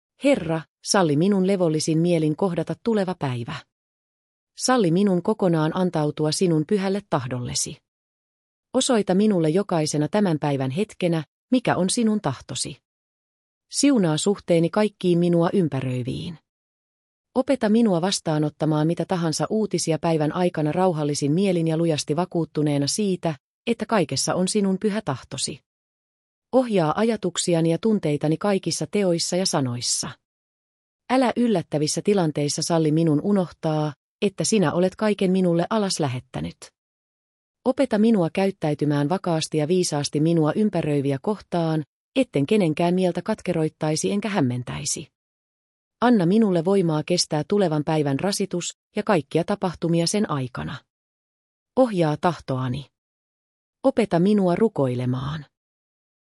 Kiireisen hiljainen hetki - rukous